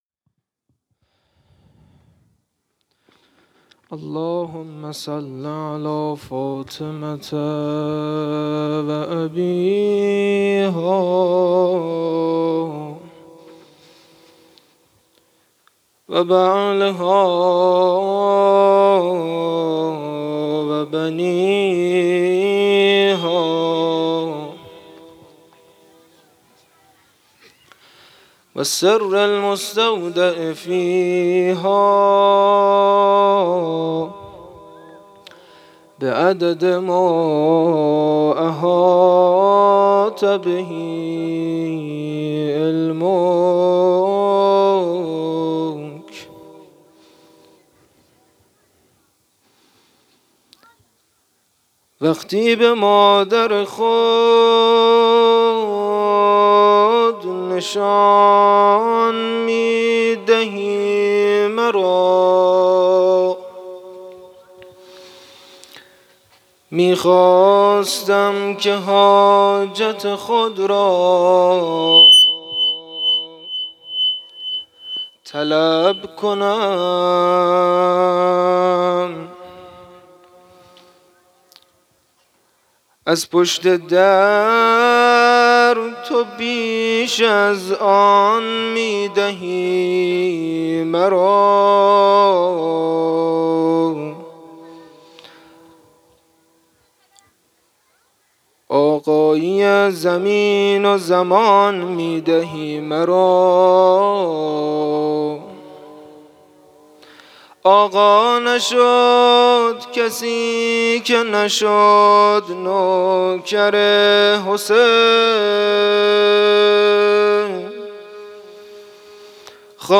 گروه هنری درخشان سازان الماس - روضه